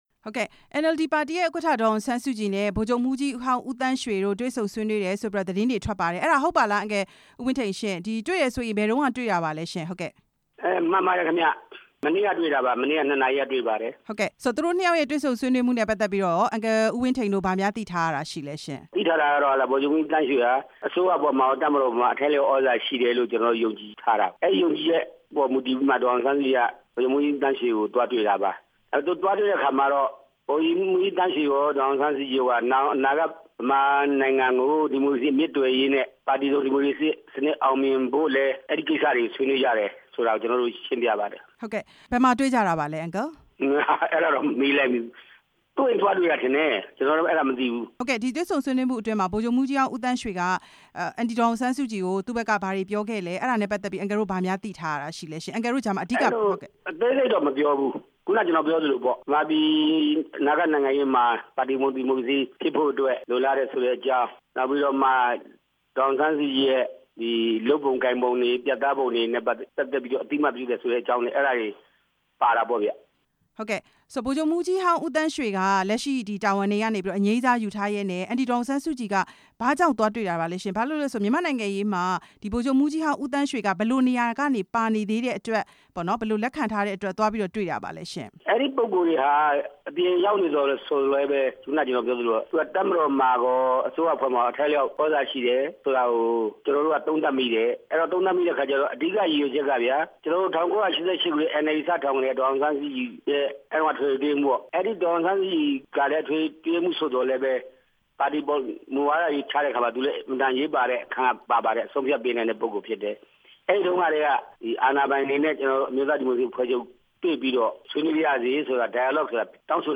ဦးသန်းရွှေနဲ့ ဒေါ်အောင်ဆန်းစုကြည်တို့ တွေ့ဆုံမှု ဦးဝင်းထိန်နဲ့ မေးမြန်းချက်